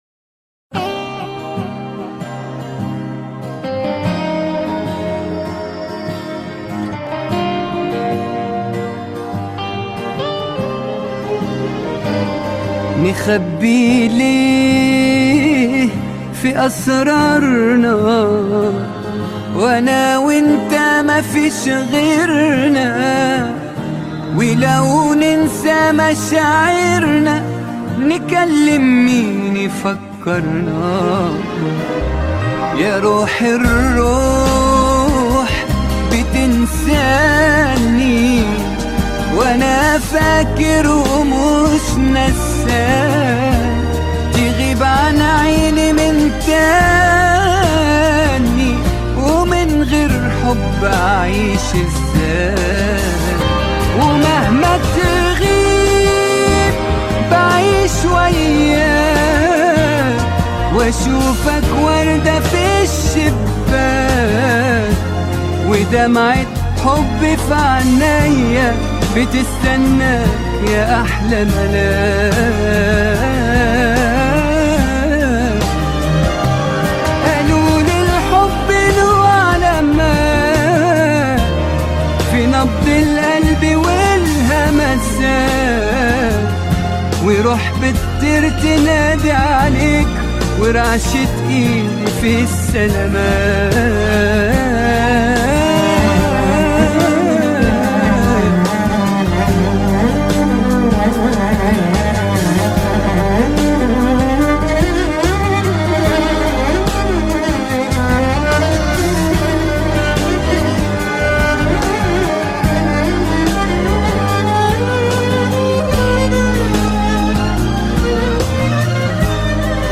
اغاني لبنانيه